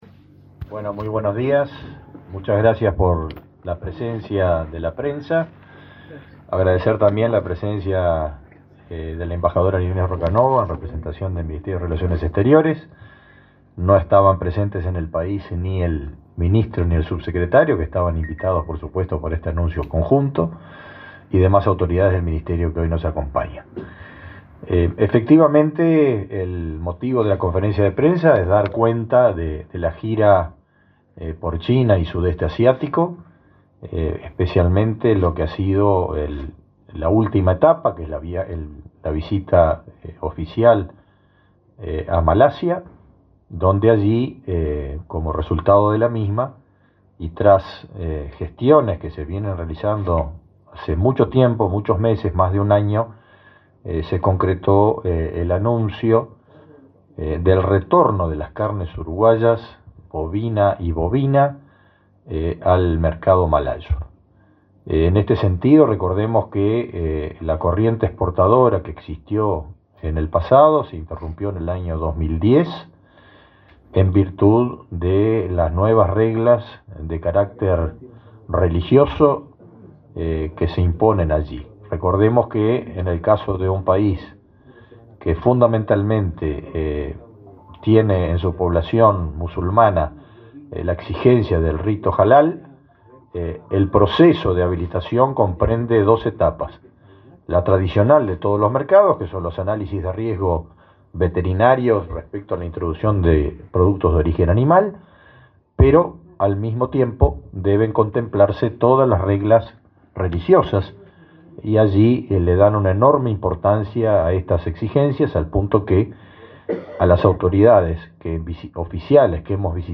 Palabras del ministro de Ganadería, Fernando Mattos
El ministro de Ganadería, Agricultura y Pesca, Fernando Mattos, realizó, junto a otras autoridades de la cartera, una conferencia de prensa para